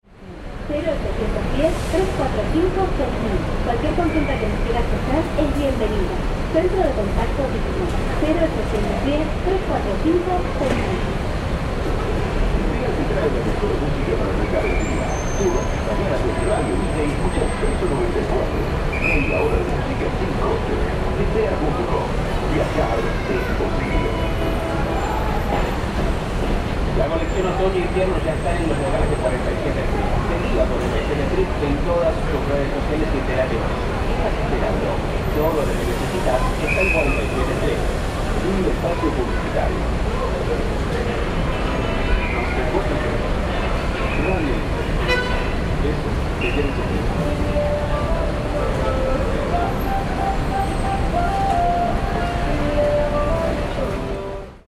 EZE airport ambience